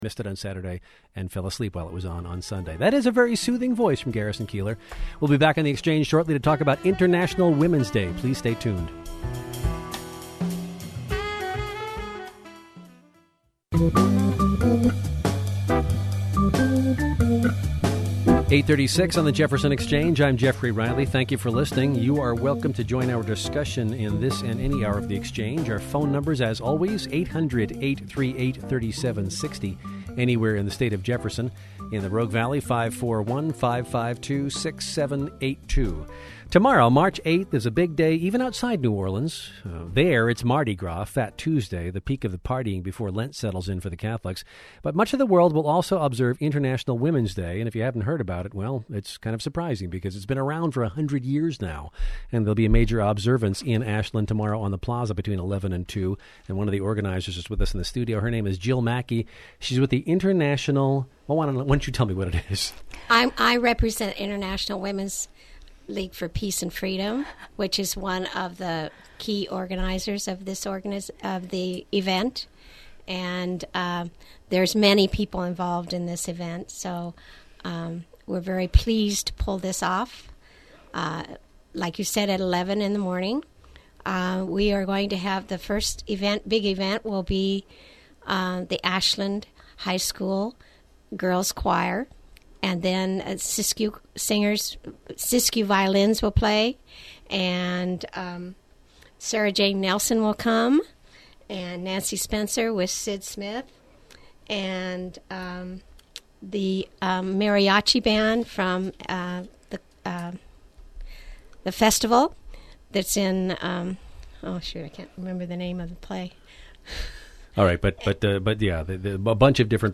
Jefferson Public Radio International Women's Day Interview